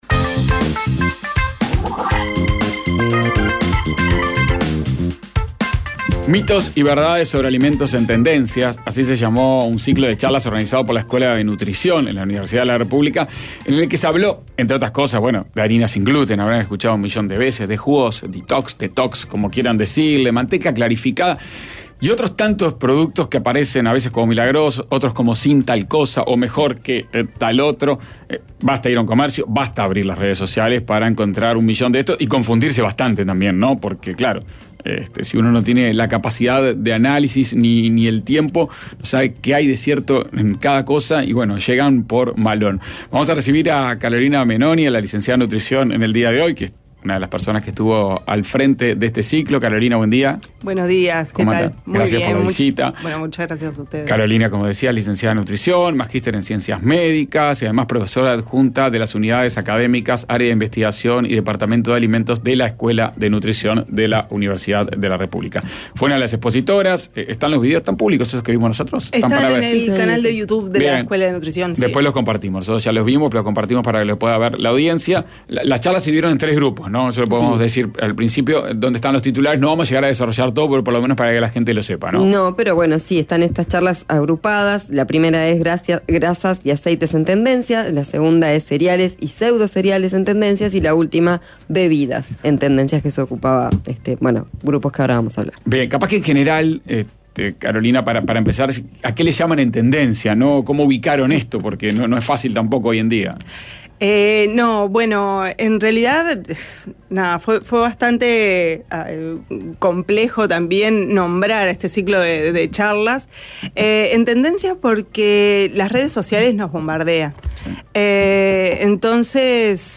Música y cantautora con casi 10 años de carrera
cantó en vivo sus canciones que no son rock